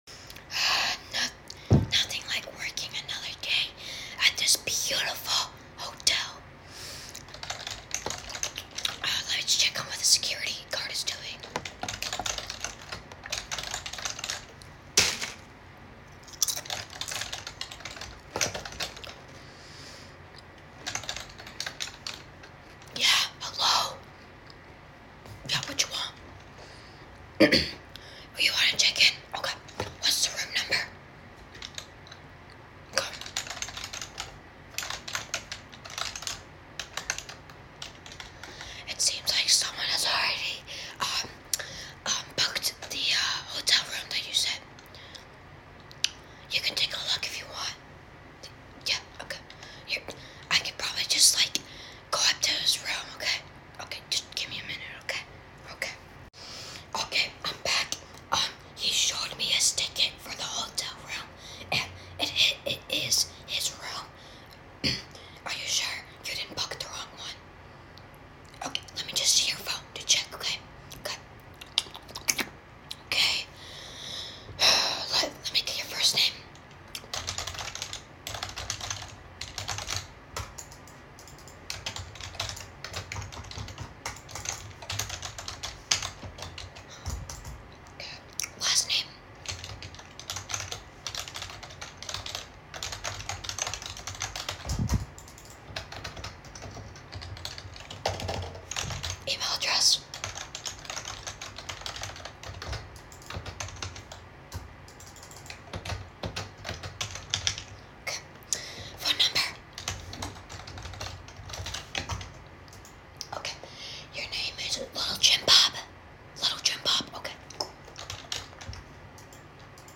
ASMR Hotel check in!
(Srry for my stutter btw) ASMR Hotel Check In!